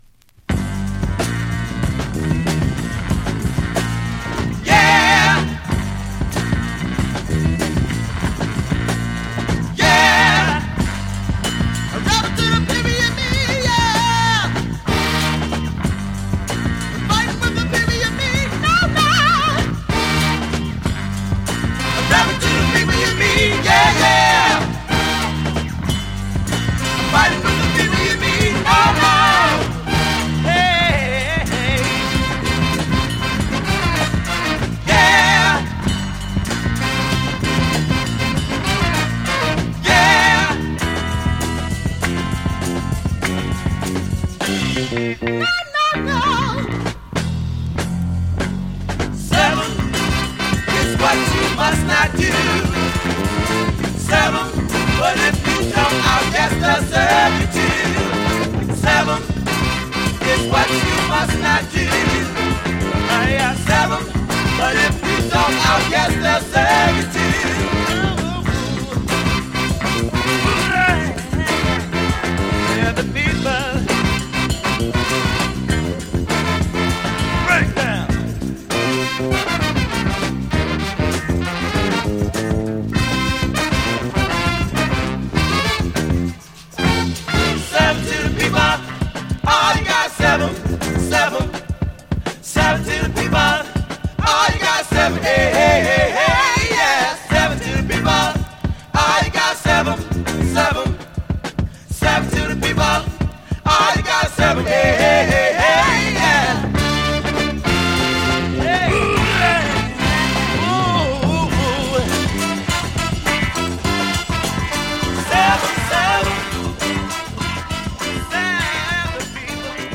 SOUL
RARE GROOVE CLASSIC !!